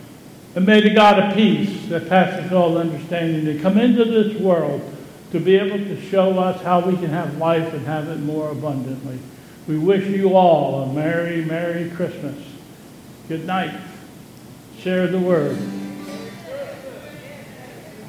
Candlelight Service